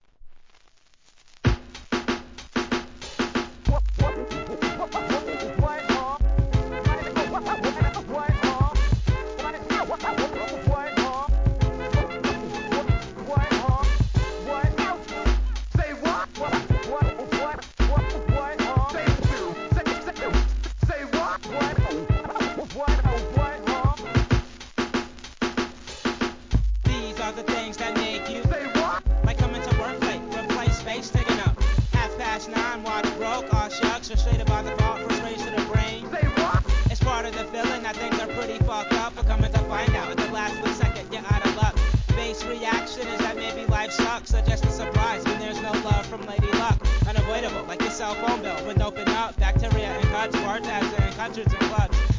アングラHIP HOP!!